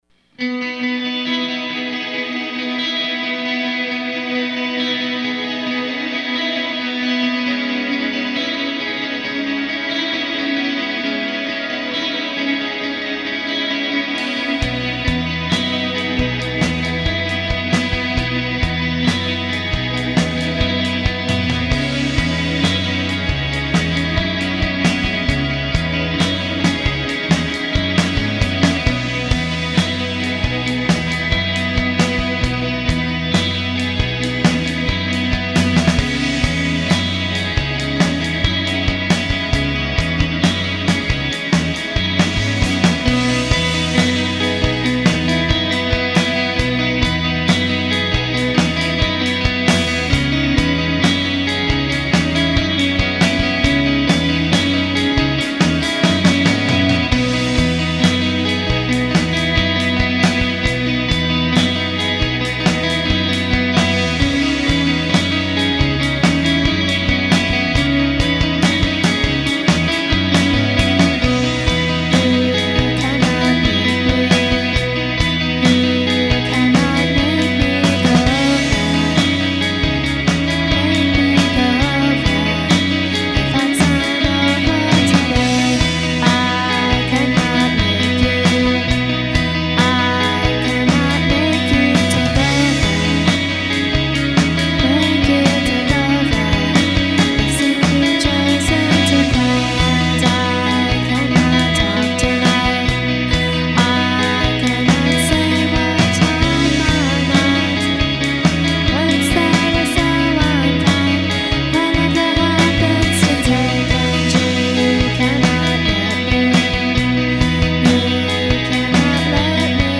unreleased basement demo